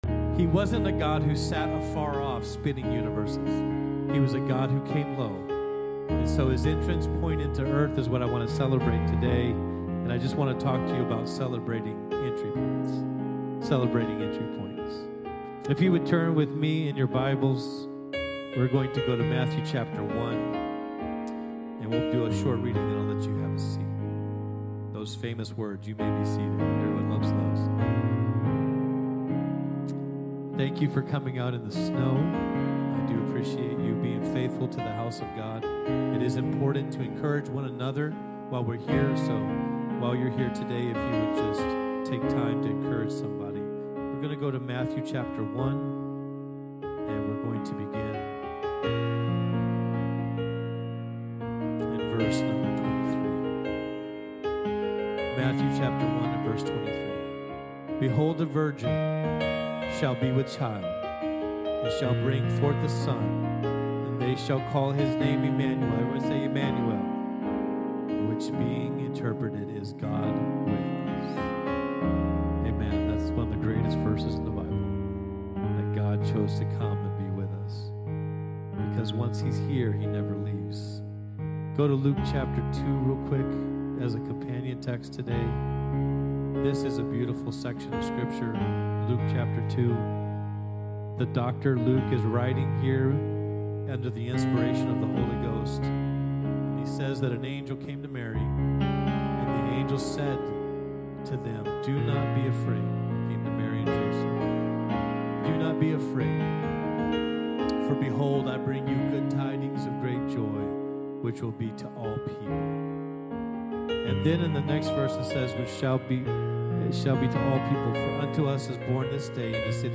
From Series: "Sunday Sermon"